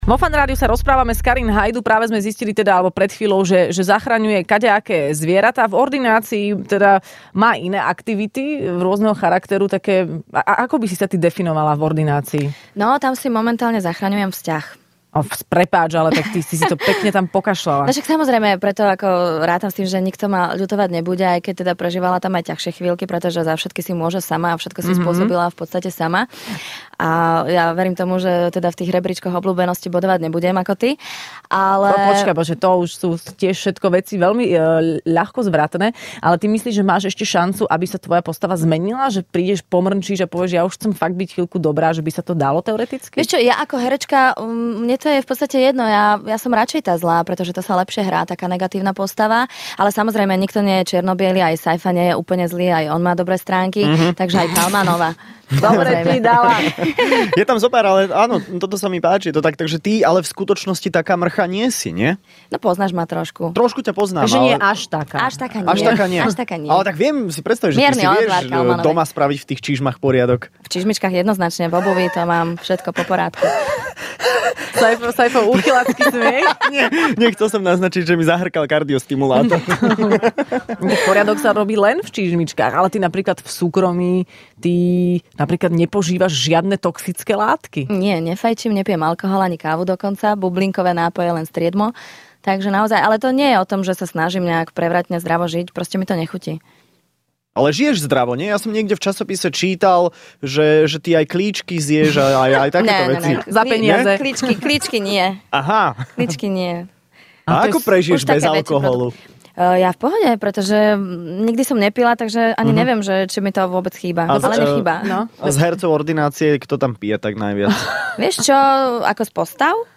V Rannej šou bola dnes hosťom herečka Karin Haydu.